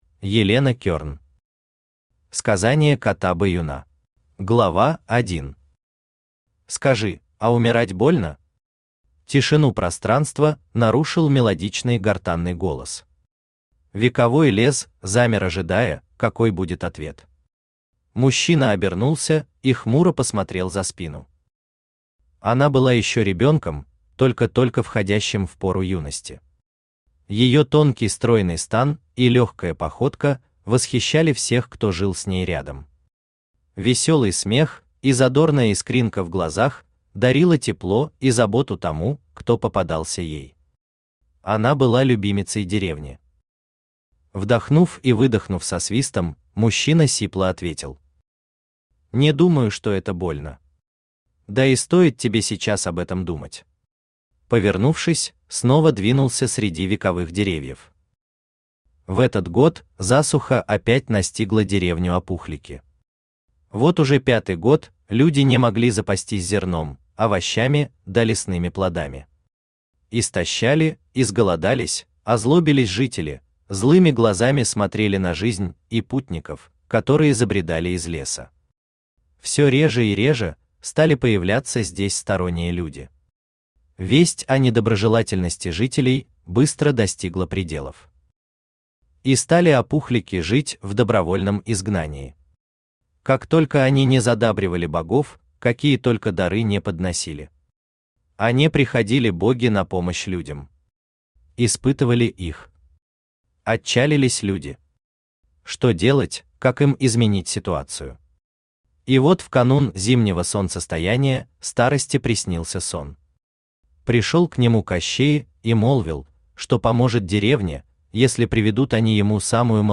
Aудиокнига Убить начало Автор Елена Кёрн Читает аудиокнигу Авточтец ЛитРес.